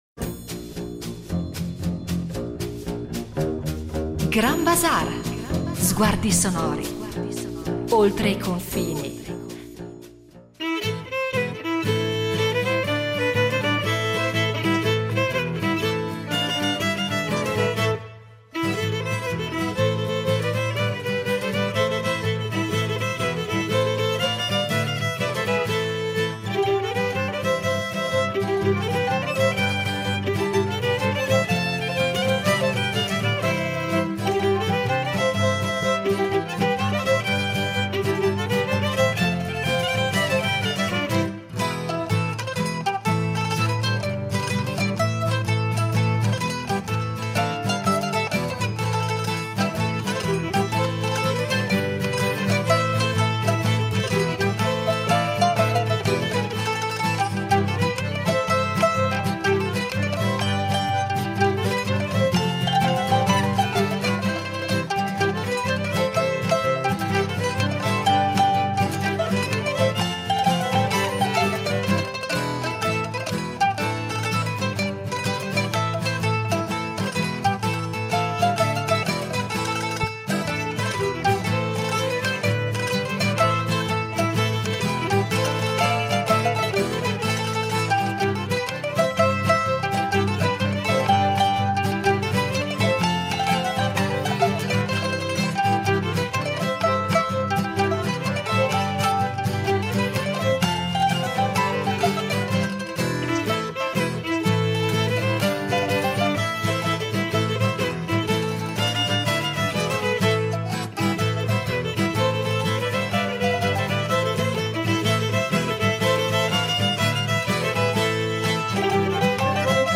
Attraverso racconti e registrazioni scopriremo come il violino sia diventato la voce del popolo, capace di reinventarsi in ogni terra e in ogni tradizione.